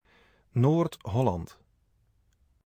North Holland (Dutch: Noord-Holland, pronounced [ˌnoːrt ˈɦɔlɑnt]